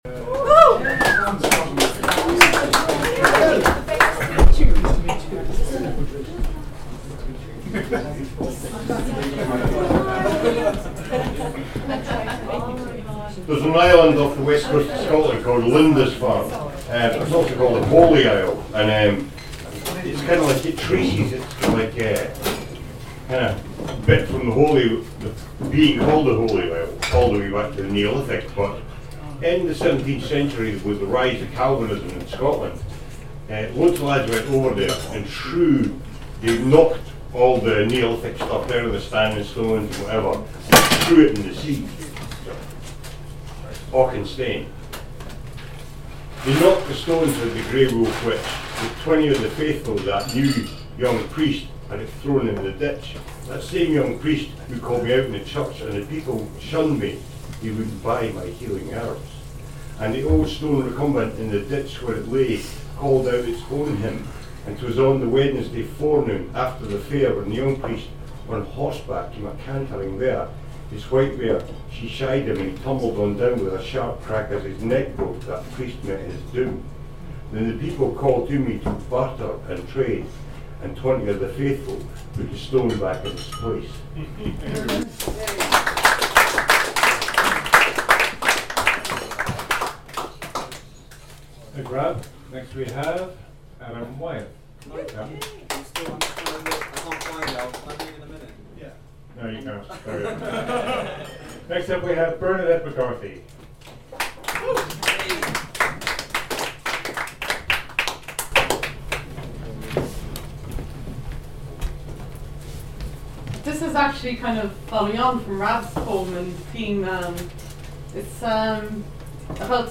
Poetry Readings & Open-Mic Nights
Open-mic night – 13th April 2015